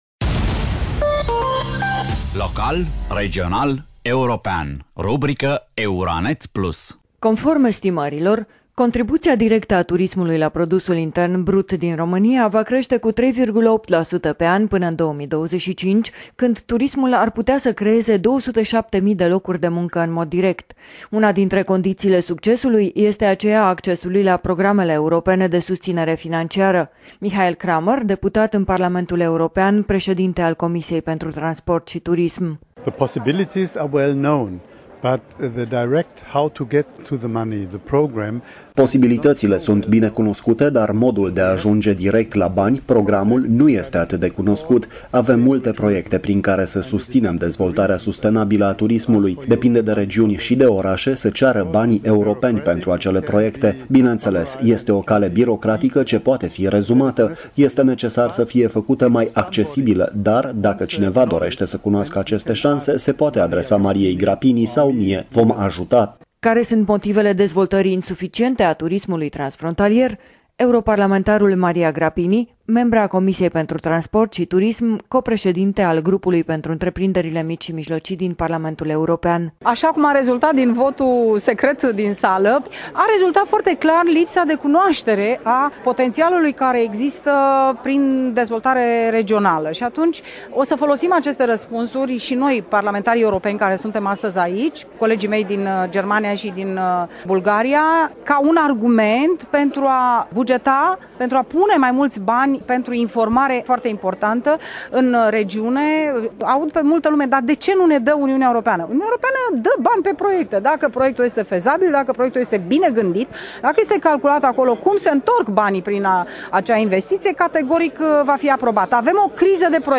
Michael Cramer, deputat în Parlamentul European, președinte al Comisiei pentru transport și turism.
Care sunt motivele dezvoltării insuficiente a turismului transfrontalier, europarlamentarul Maria Grapini, membră a comisiei pentru transport și turism, co-președinte al grupului pentru IMM-uri din Parlamentul European.